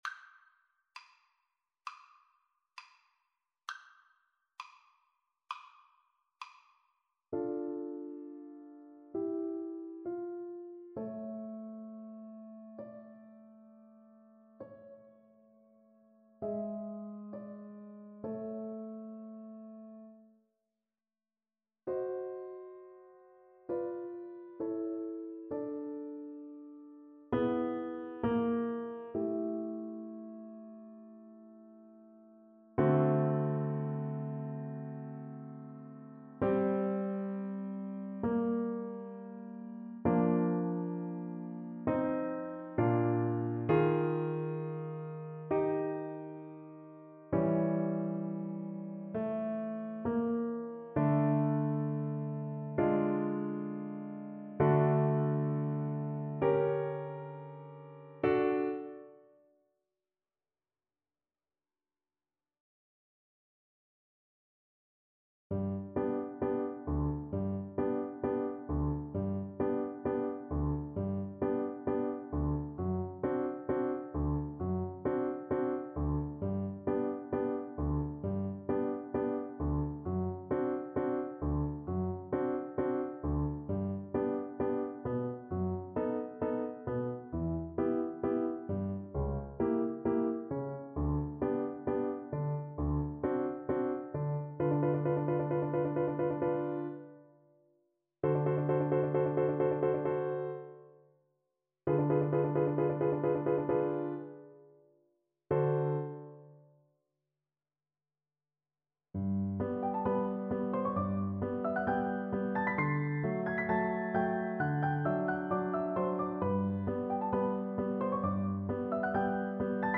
4/4 (View more 4/4 Music)
Adagio =66
Classical (View more Classical Flute Music)